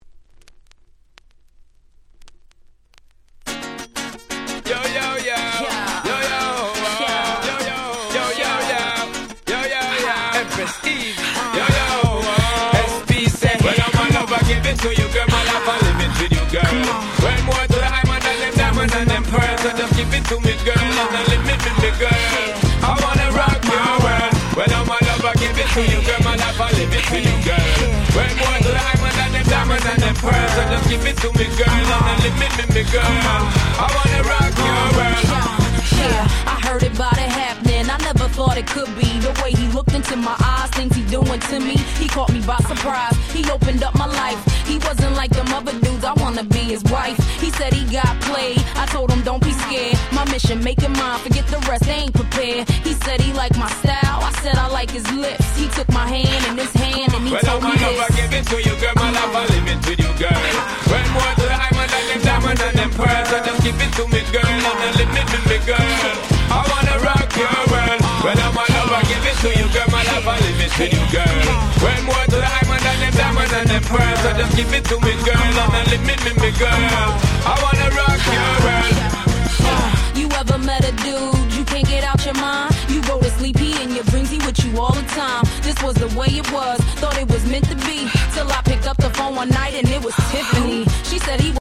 07' Smash Hit Hip Hop !!
Dancehall Reggae風味のBeatも気持ち良い！！
レゲエ ダンスホール